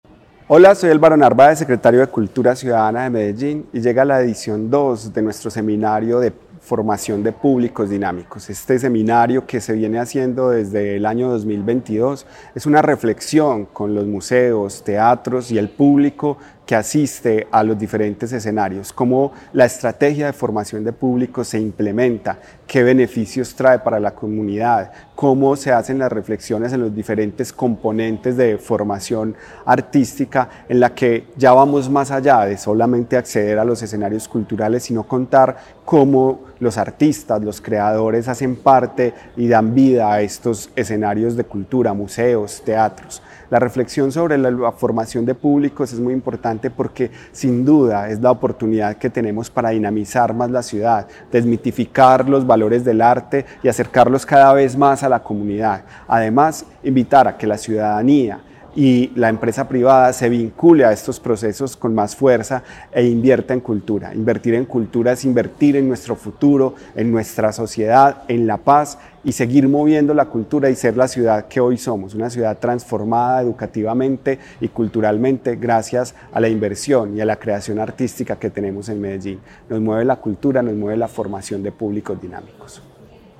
Palabras de Álvaro Narváez Díaz, secretario de Cultura Ciudadana.